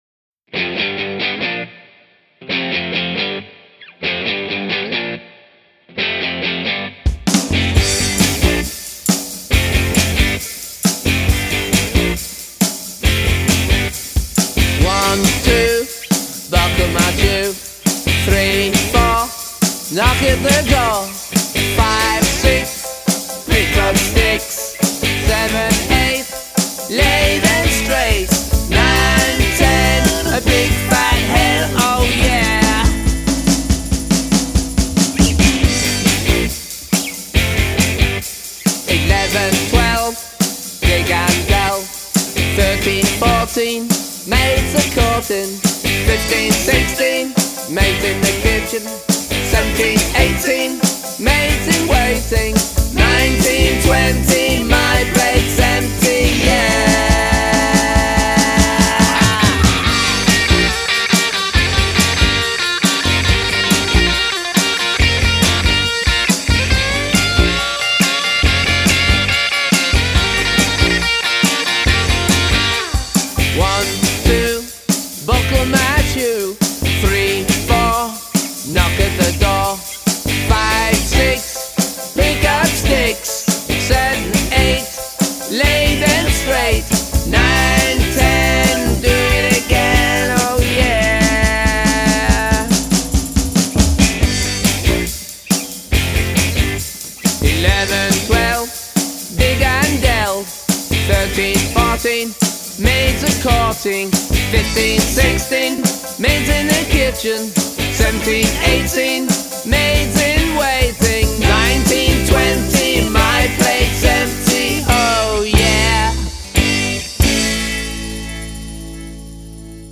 Counting SongsRock